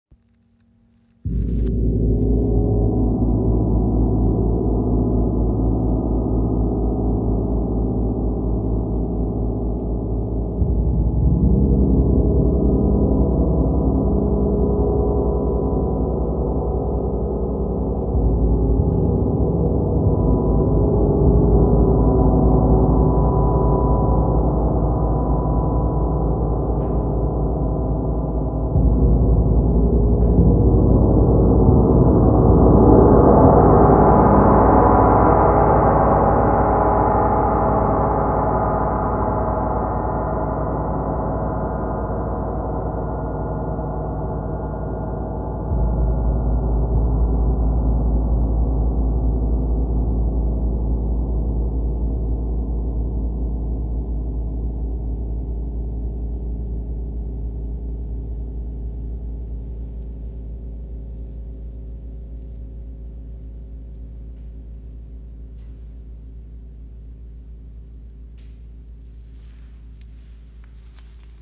Klangbeispiel
Ihre einzigartigen Oberflächen sind aufwändig gehämmert und sorgen nicht nur für ein grossartiges Design, sondern auch für einen ganz besonderen Klangcharakter.